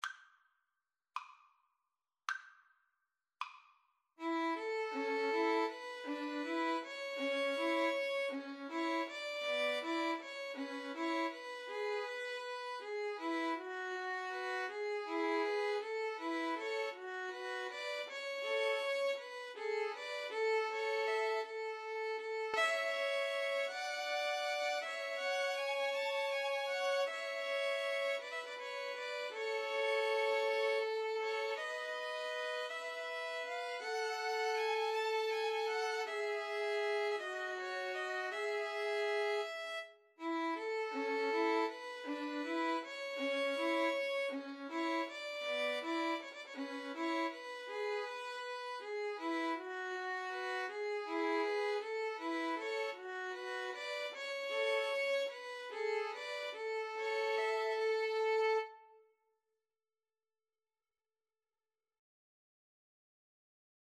6/8 (View more 6/8 Music)
Andante
Pop (View more Pop Violin Trio Music)